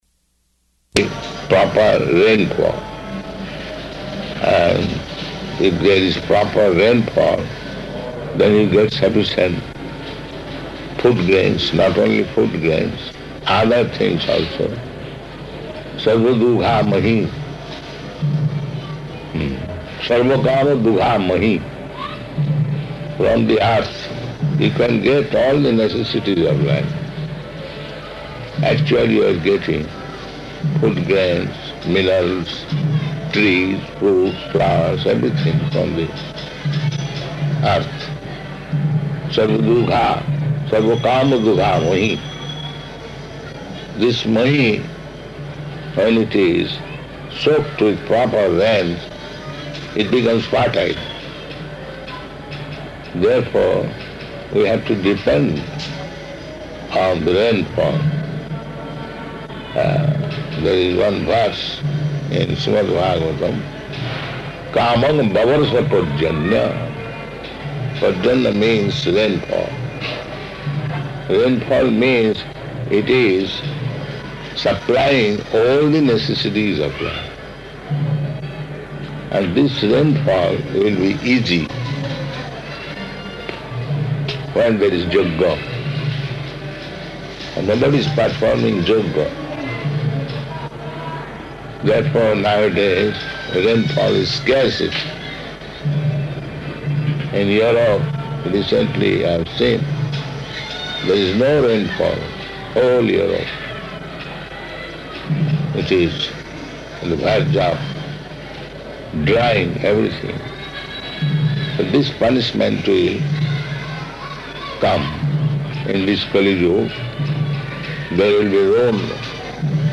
Evening Darśana --:-- --:-- Type: Conversation Dated: December 3rd 1976 Location: Hyderabad Audio file: 761203ED.HYD.mp3 Prabhupāda: ...there will be proper rainfall.